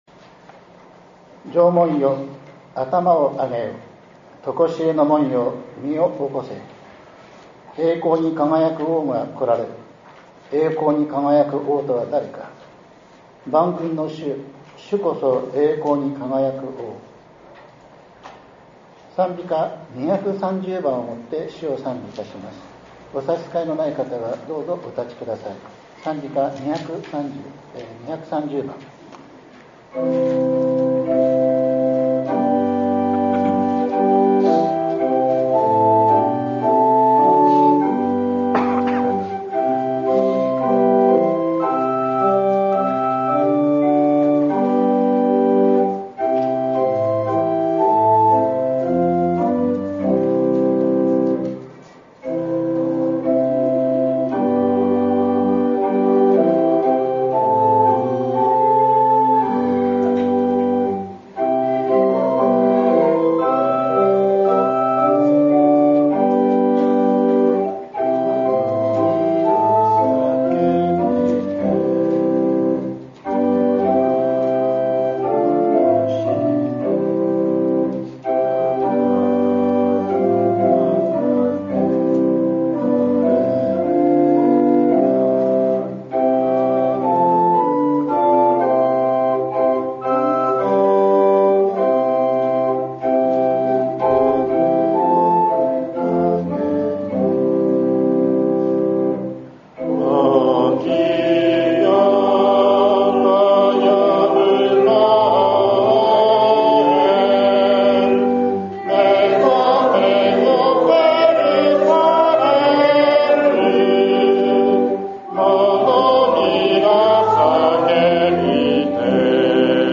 １２月７日（日）降誕節第２主日礼拝